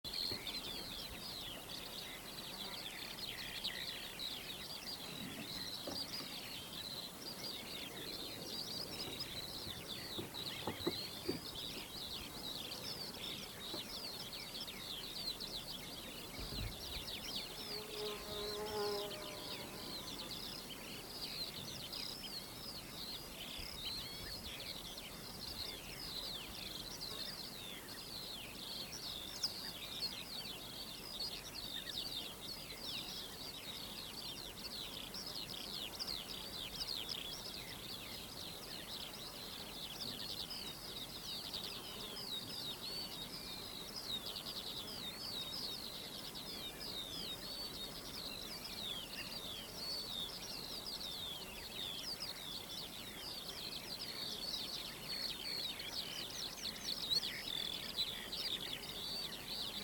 Шепот ветра на лугу